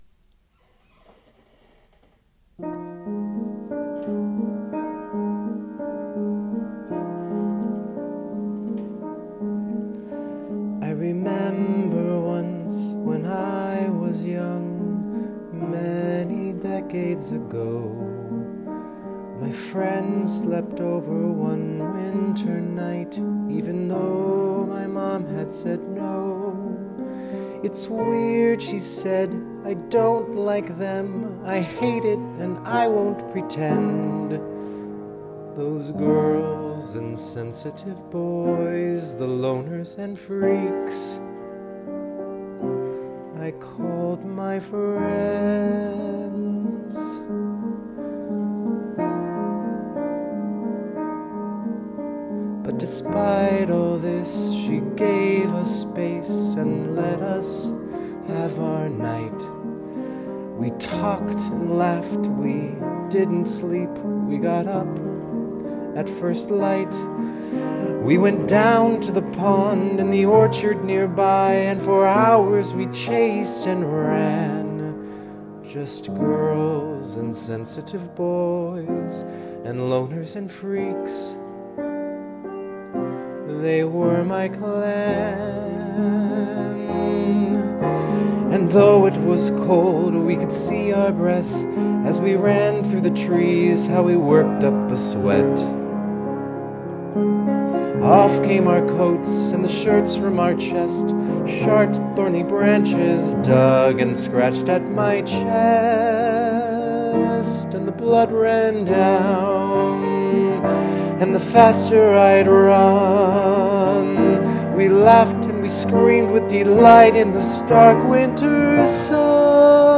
Here are some rough demo tracks from Mutant Water Babies.